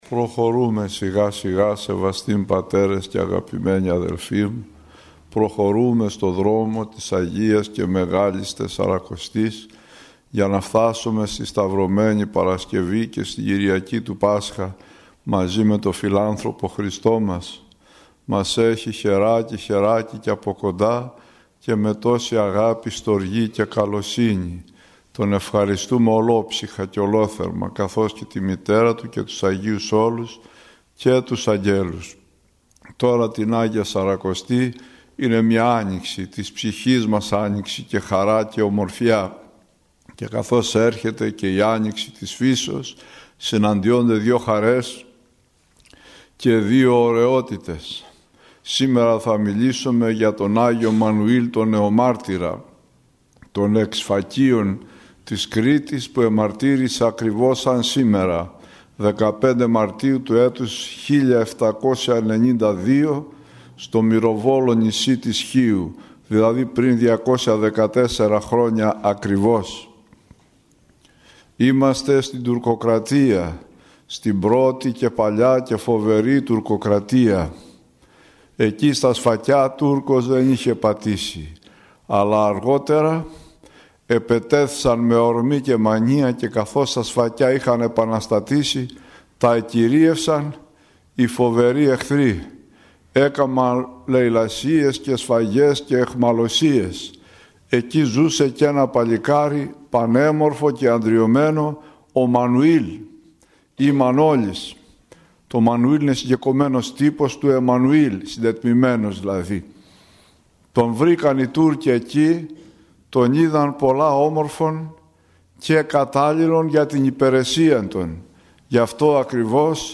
ηχογραφημένη ομιλία